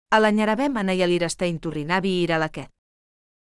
Melorin is renowned for its melodic and song-like quality, with a rhythmic cadence that flows effortlessly.
Example sentences